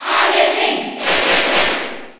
Category:Crowd cheers (SSB4) You cannot overwrite this file.
Link_&_Toon_Link_Cheer_French_NTSC_SSB4.ogg